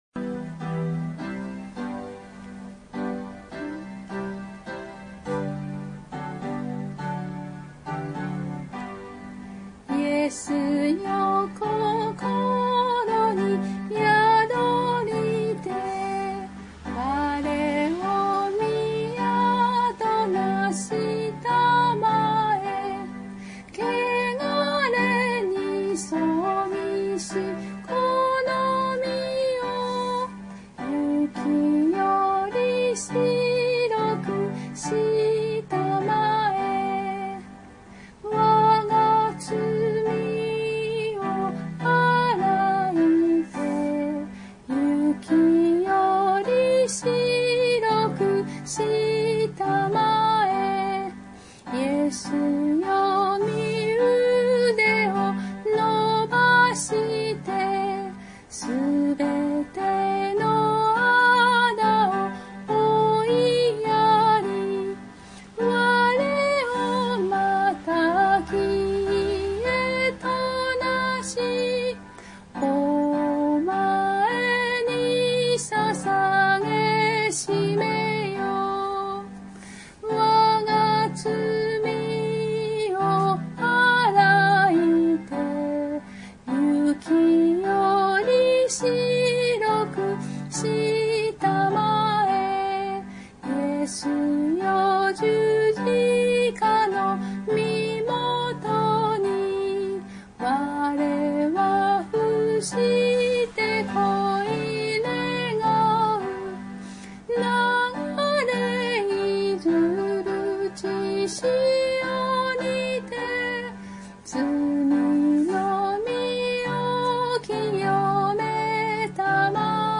9） 「イエスよ心に宿りて」 賛美歌521